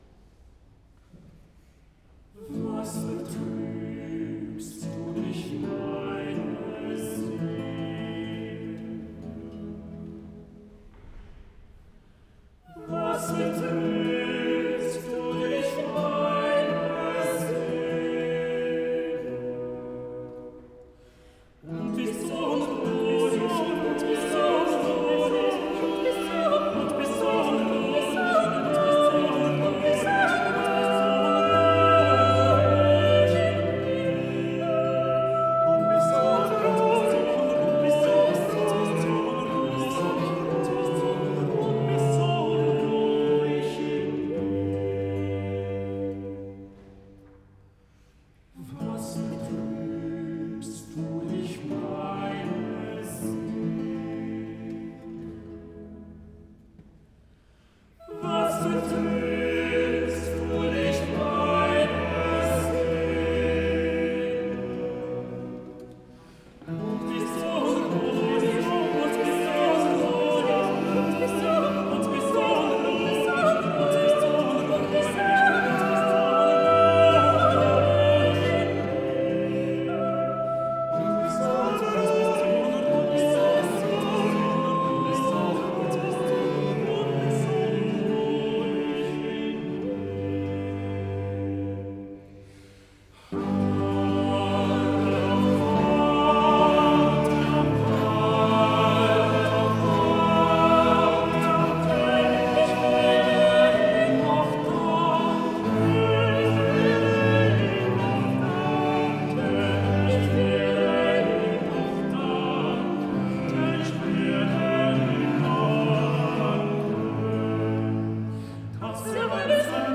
Avec l’autorisation de RTS-Espace 2, vous pouvez ici réécouter le concert des Fontaines d’Israël de Schein donné à la collégiale de St-Ursanne en 2018.